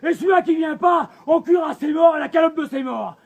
la calotte de ses morts Meme Sound Effect